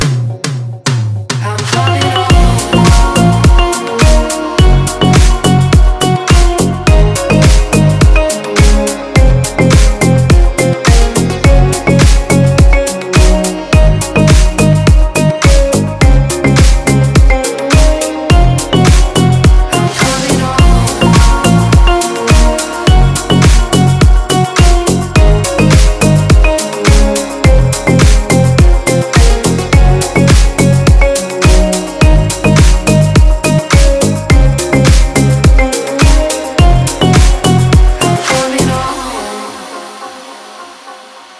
• Качество: 320, Stereo
женский вокал
deep house